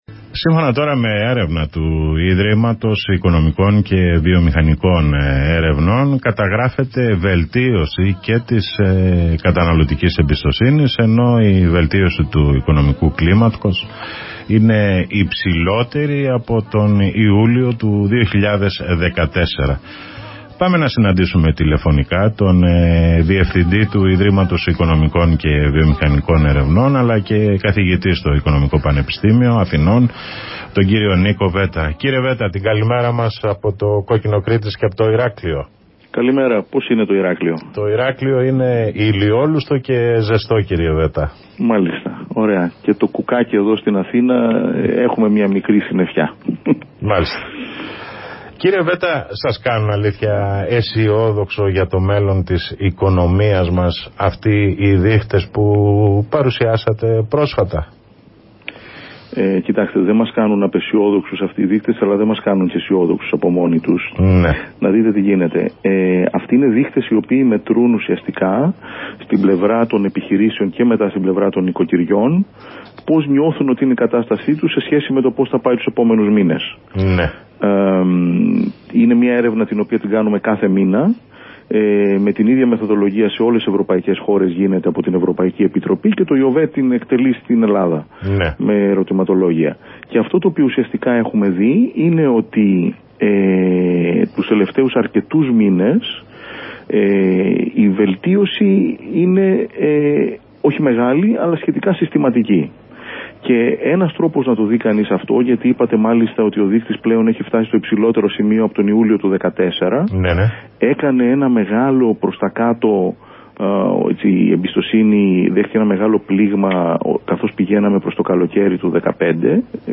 Συνέντευξη Στο Κόκκινο 88,4 FM